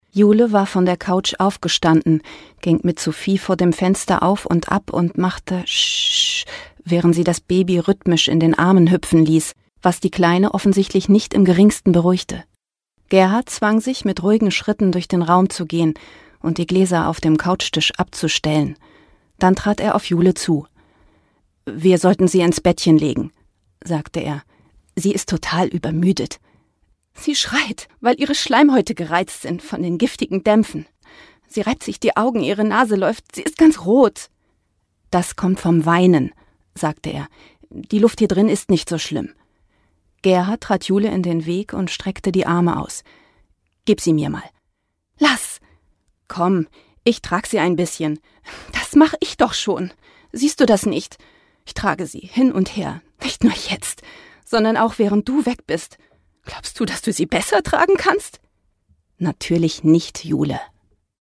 Teaser Firefox Browser Image Werbung